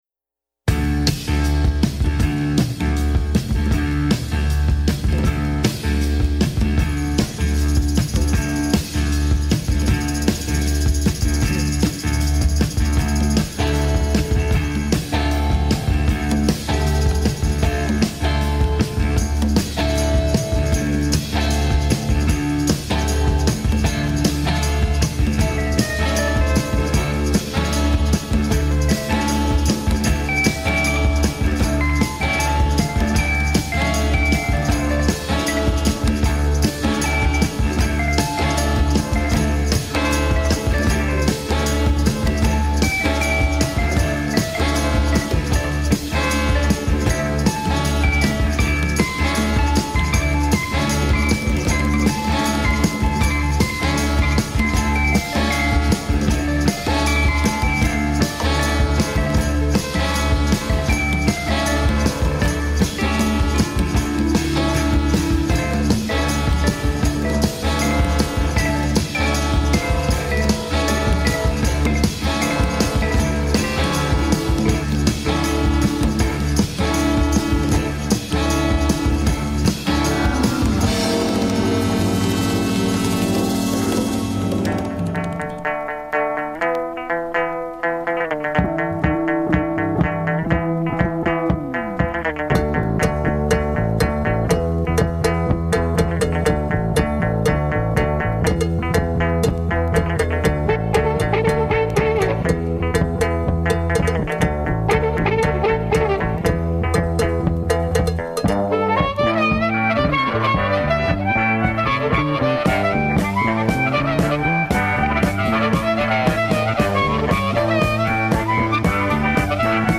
Disco/House Funk/Soul Rock